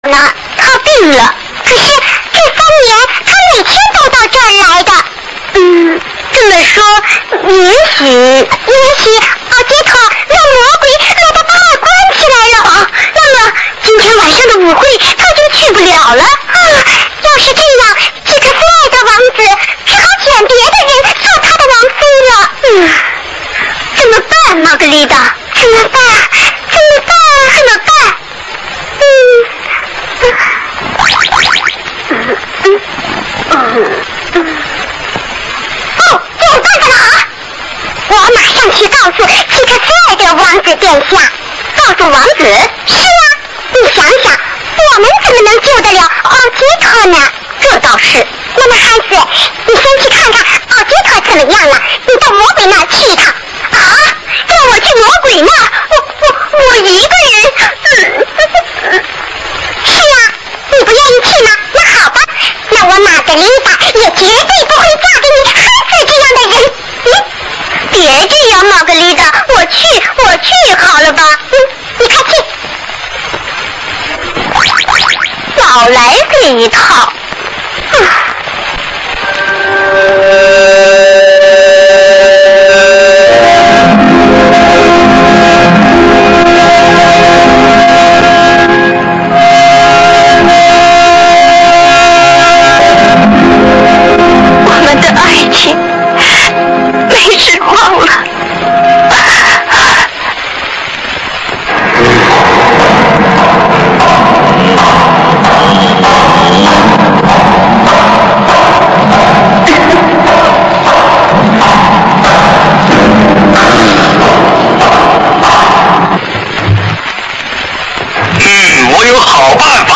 上海电影译制厂译制
主要配音演员：
王子--童自荣
奥杰塔--刘广宁
王后--李梓
奥黛尔--丁建华